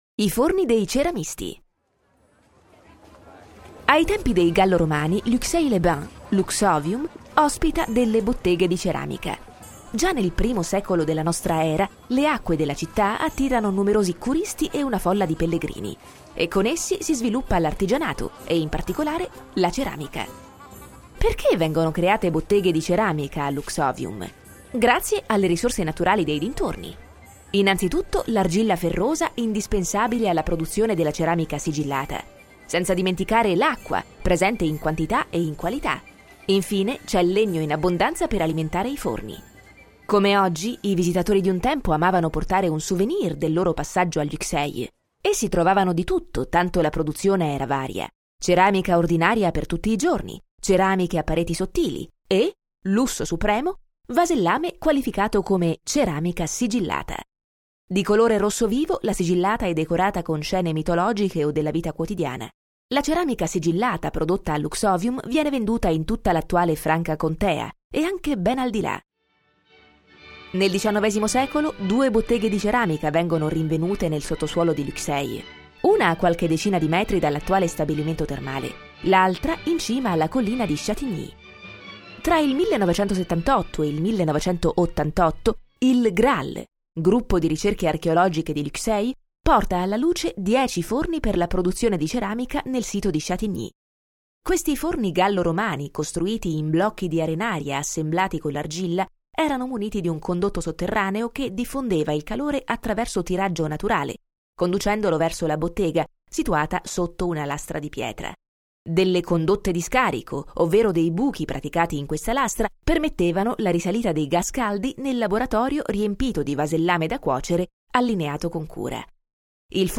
Balade Audio – 12 Les Fours de potiers gallo-romains
Explications audio